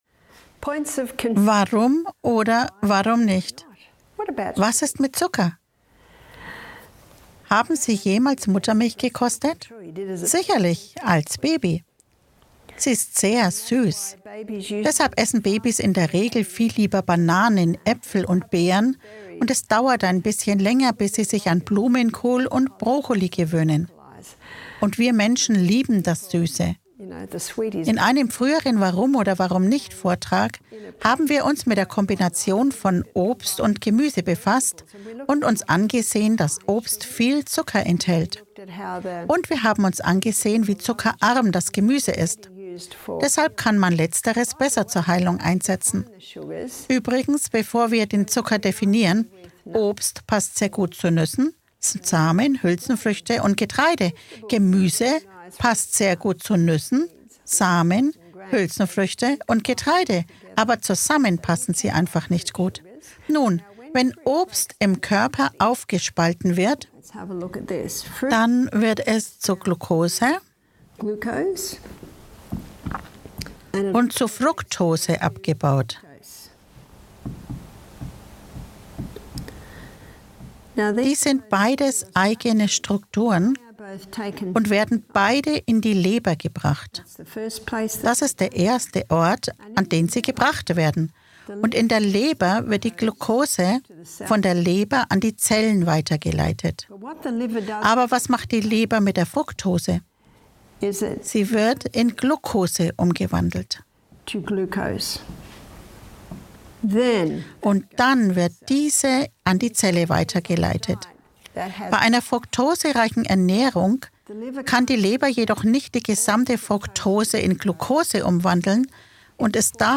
In einem aufschlussreichen Vortrag wird die Zuckerfalle untersucht und beleuchtet, wie Fructose im Körper metabolisiert wird.